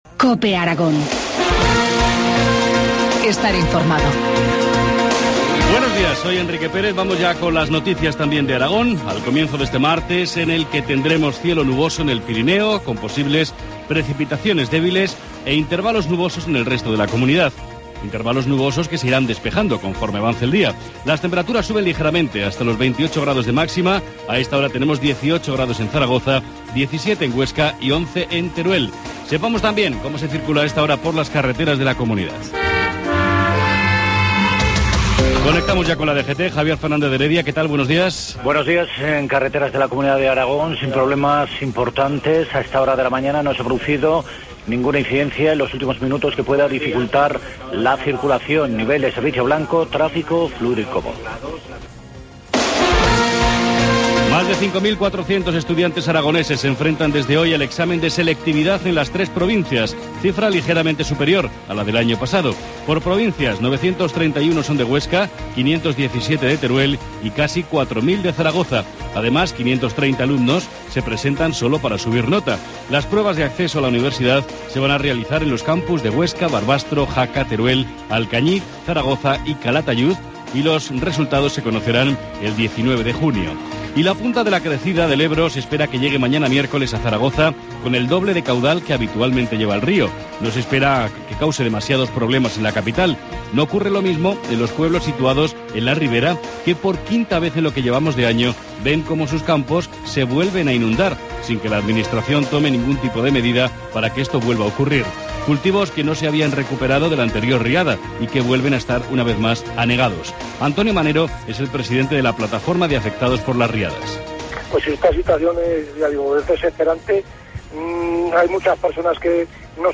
Informativo matinal, martes 11 de junio, 7.25 horas